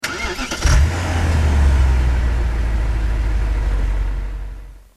Home gmod sound vehicles tdmcars morganaero
enginestart.mp3